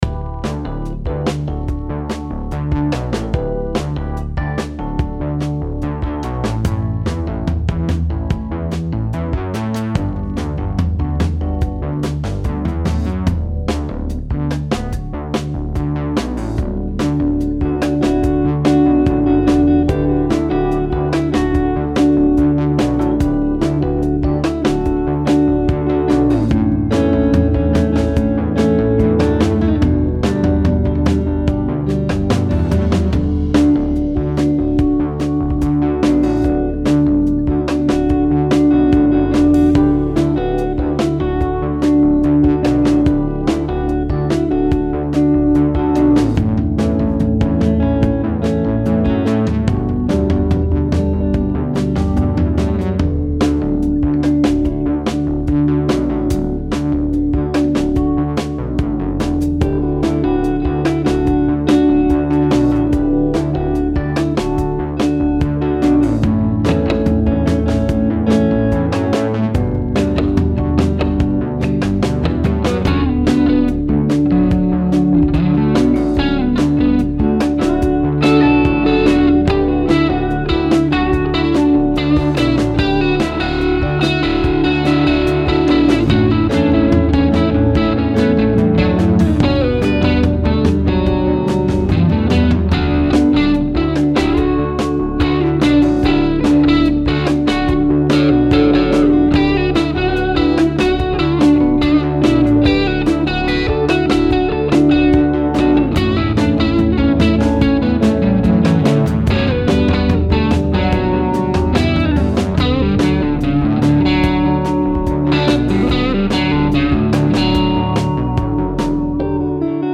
Download Test Guit 70s Drums Mx1